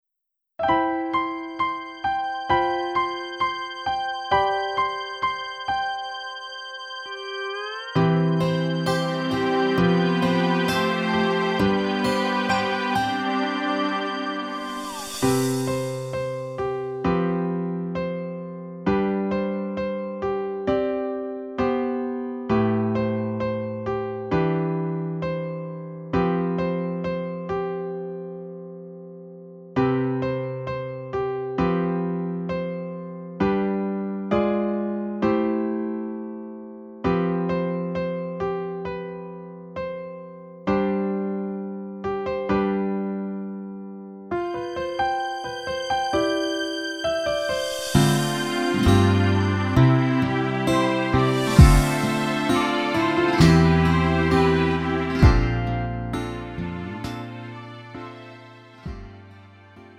음정 원키 3:34
장르 가요 구분 Lite MR